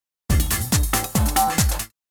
强节奏鼓声转场-2
【简介】： 超强节奏鼓声